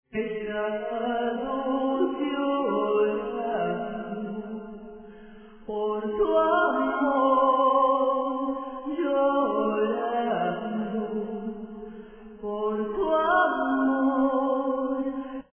Triest
cry.wav